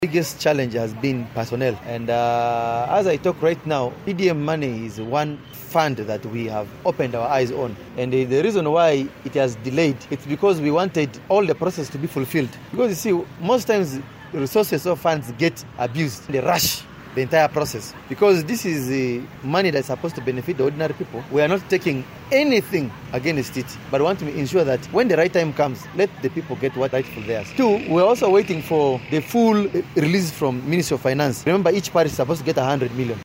Hon. Victor Vidal Lomori, the Deputy Speaker of Arua Central, shed light on the situation by explaining that the funds are available. He acknowledged that there were minor technical challenges that caused the delay but assured the residents that efforts are being made to resolve these issues promptly.